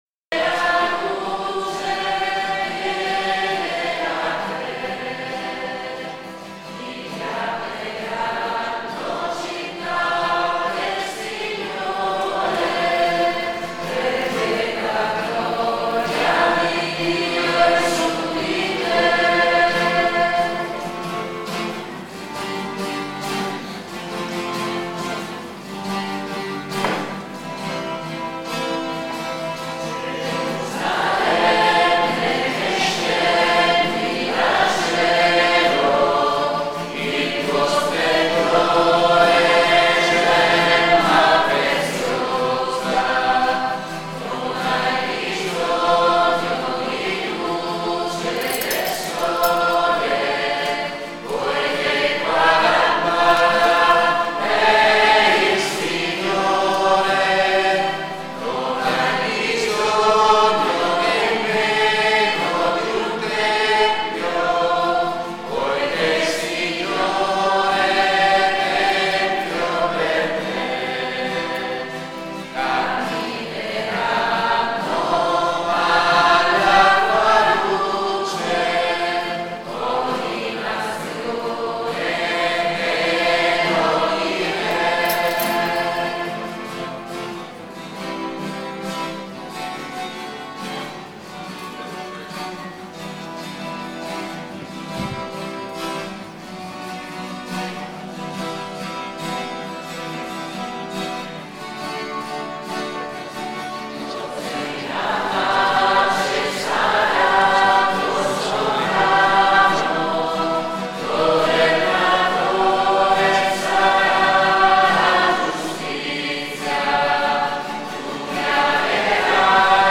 Omelia dell'arcivescovo mons. Giacomo Morandi in occasione del funerale
Chiesa del Castello - San Polo d'Enza